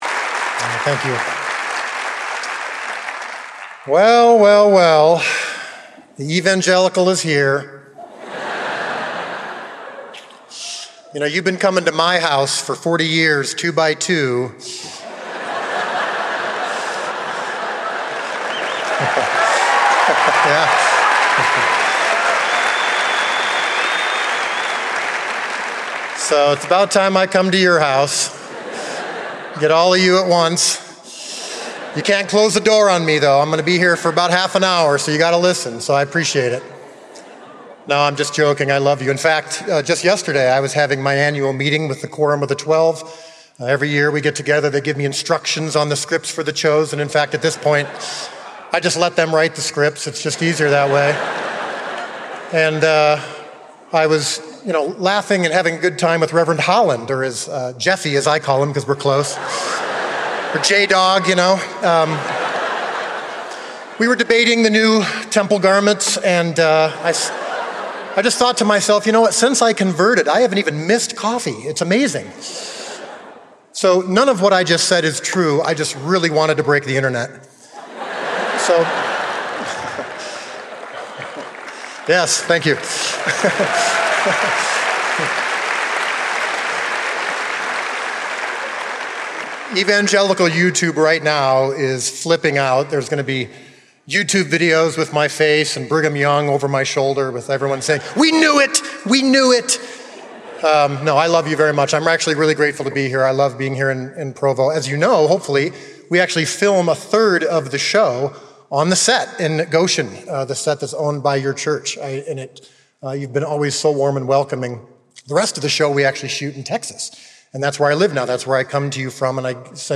Dallas Jenkins, creator of “The Chosen,” delivered this forum address on October 29, 2024.